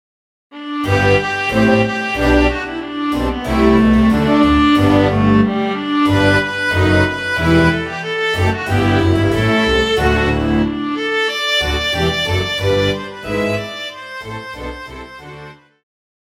Classical
Concerto
Solo with accompaniment